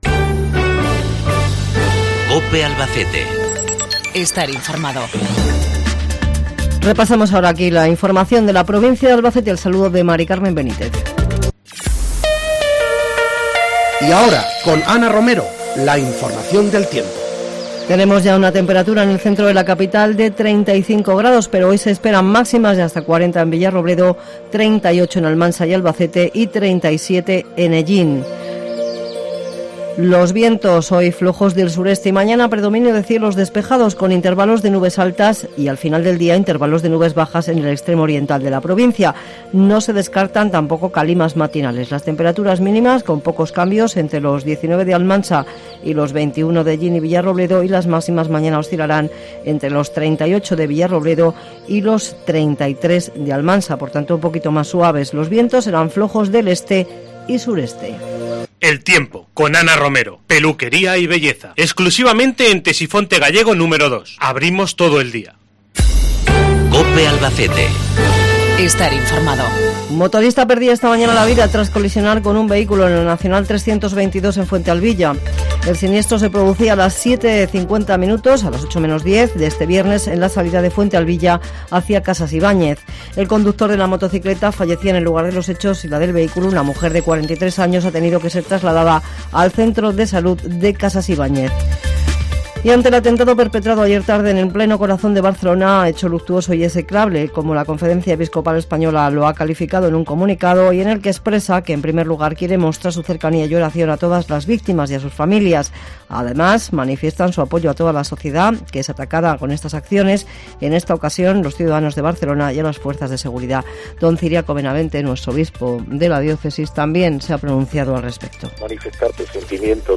Informativo Mediodia en Albacete